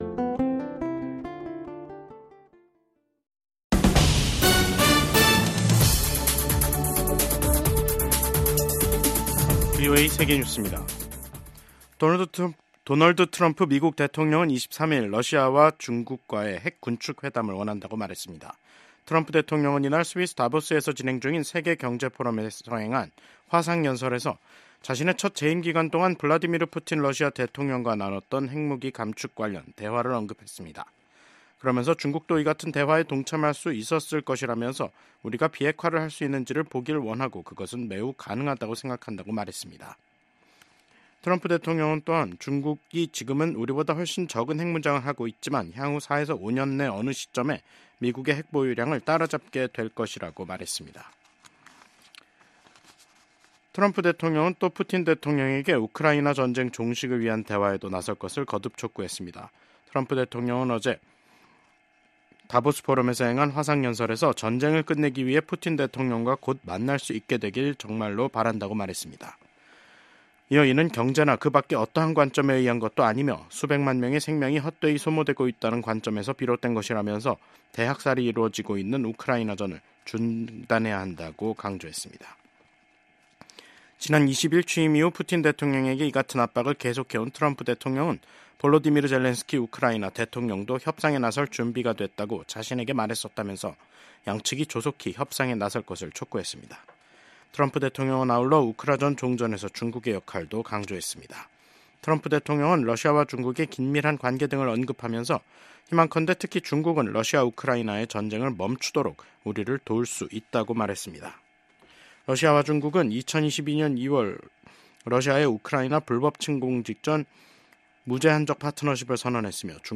VOA 한국어 간판 뉴스 프로그램 '뉴스 투데이', 2025년 1월 24일 2부 방송입니다. 도널드 트럼프 미국 대통령이 김정은 북한 국무위원장과 다시 만날 것이라는 의지를 밝혔습니다.